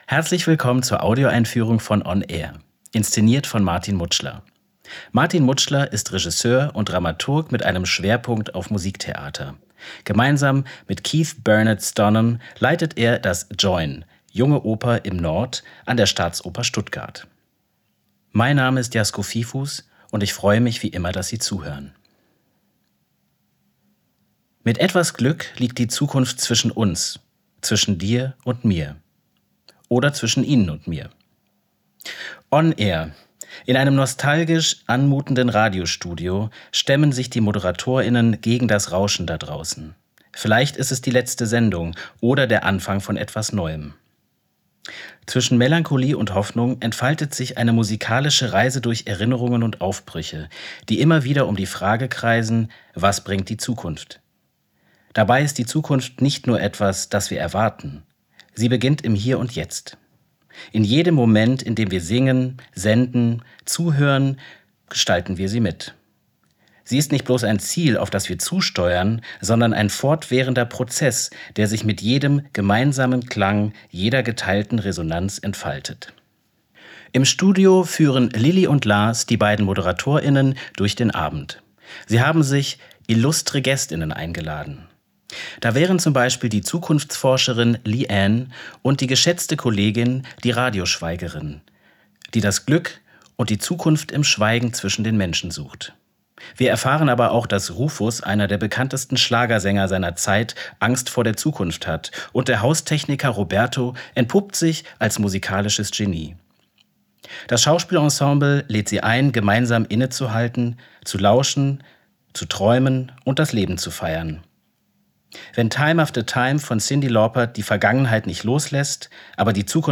tdo_einfuehrung_onAir.mp3